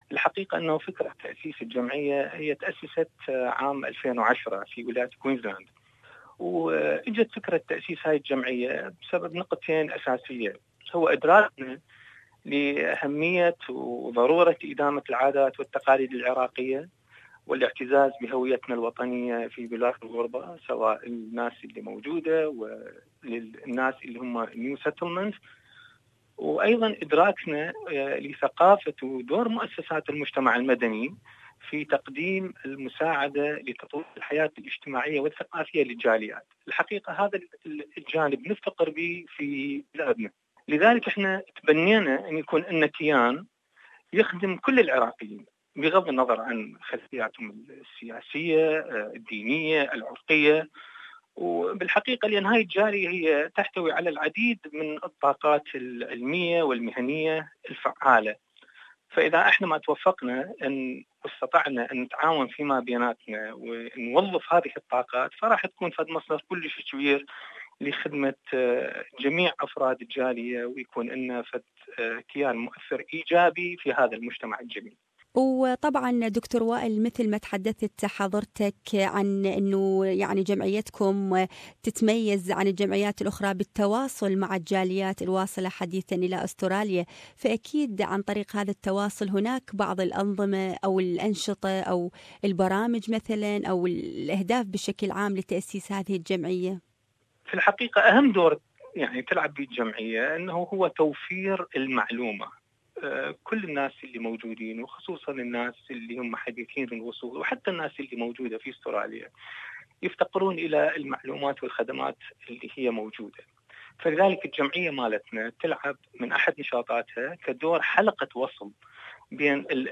Iraqi Unity Association in Queensland provides newly arrived people to Australia with different kinds of services. To know more about these services, listen to this interview